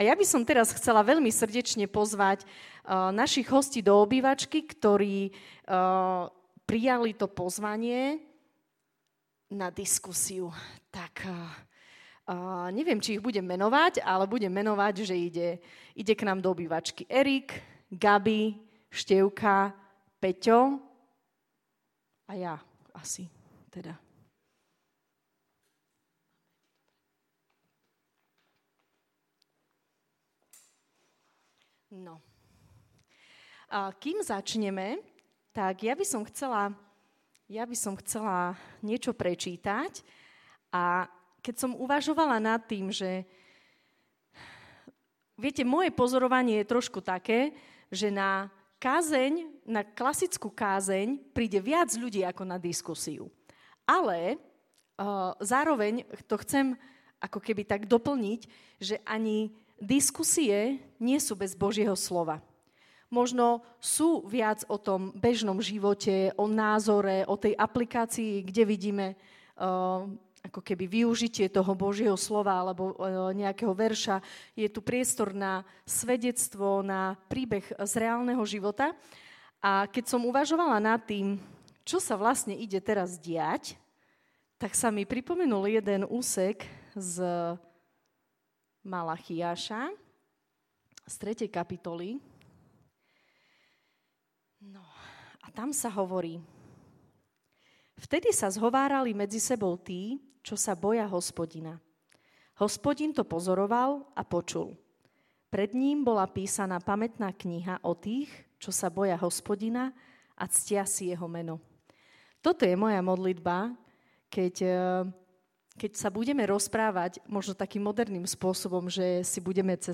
Panelová diskusia: Modlitba, pôst, almužna, chvála